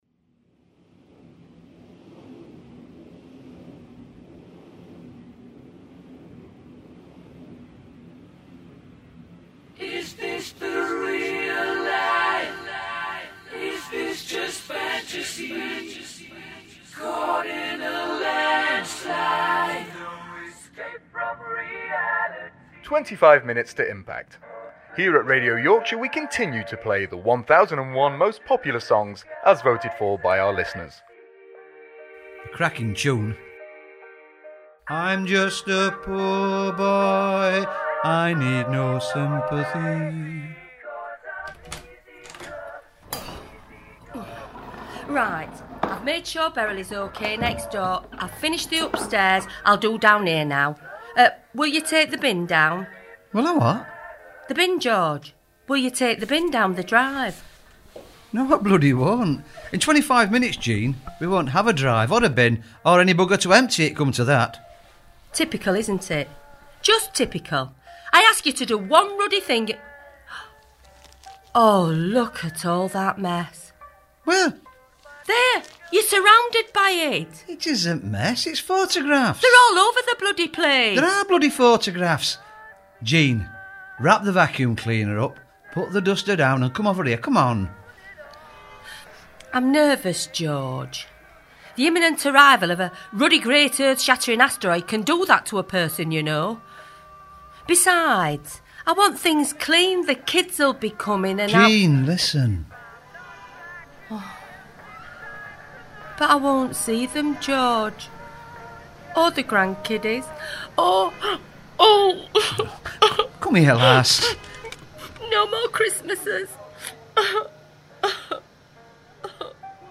I Love You Jean Benedict - Radio AudioDrama
How would you spend the last few moments of your existence, would it be with the one you love? This is our new radio audiodrama on youtube.